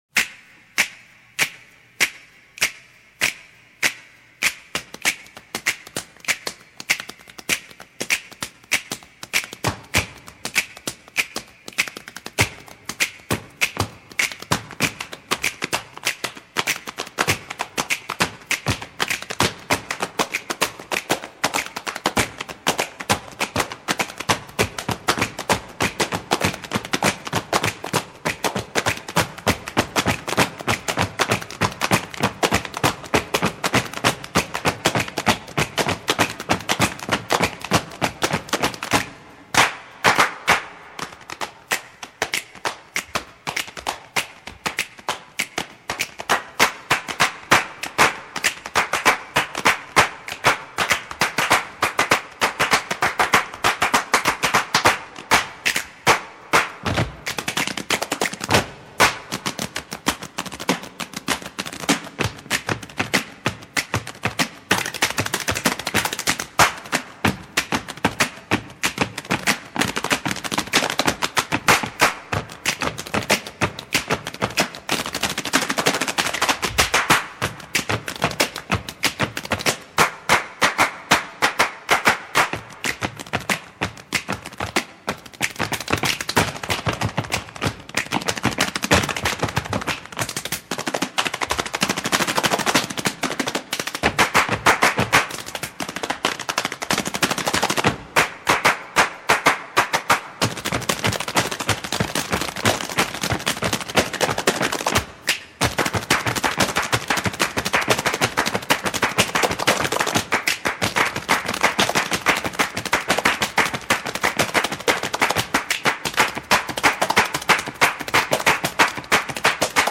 Voicing: Mallet Choir